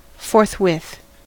forthwith: Wikimedia Commons US English Pronunciations
En-us-forthwith.WAV